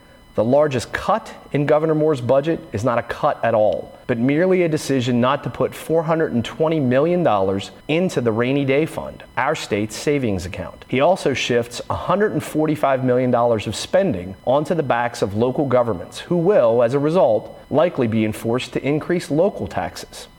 Buckel Leads Republican Response To Governor’s Speech